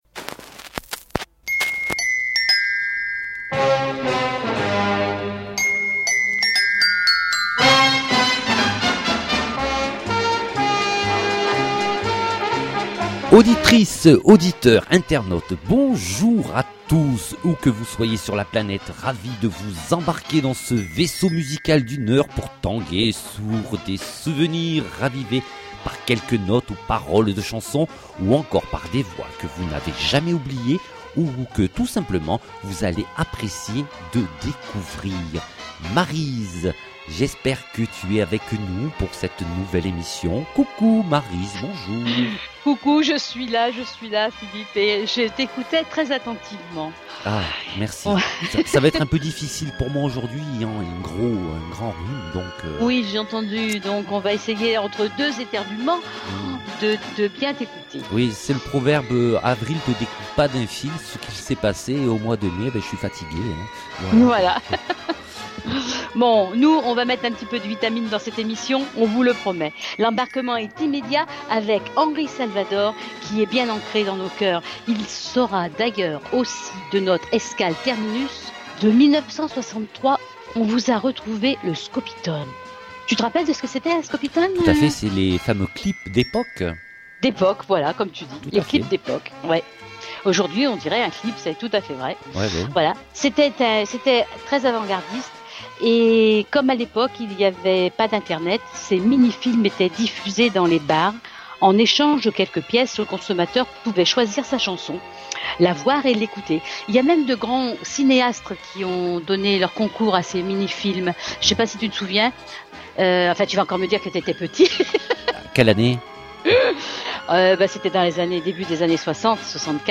Le Podcast Journal, en partenariat avec Radio Fil, vous propose cette émission musicale dédiée aux années vinyles